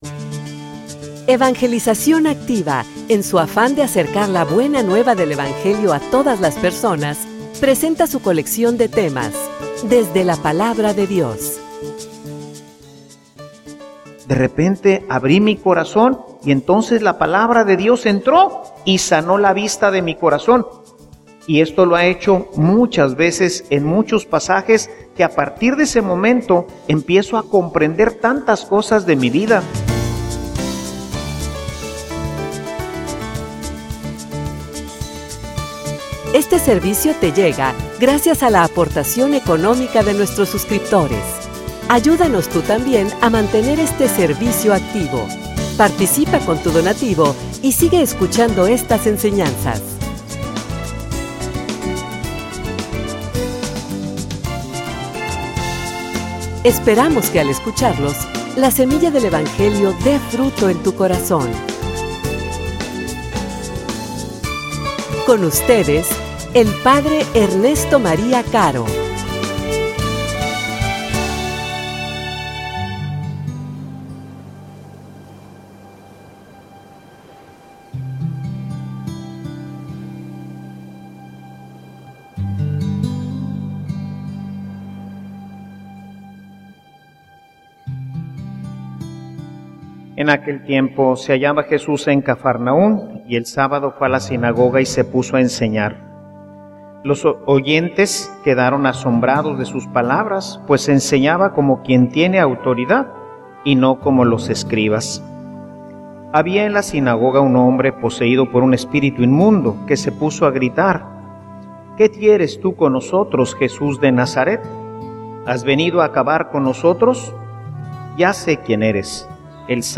homilia_Una_palabra_con_poder.mp3